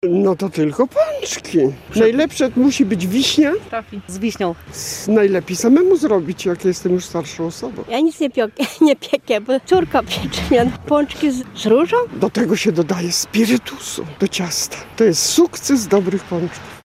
Sonda: Co Łomżanie jedzą w Tłusty Czwartek?
Z okazji Tłustego Czwartku zapytaliśmy mieszkańców Łomży, za jakie słodkości tego dnia sięgają najchętniej.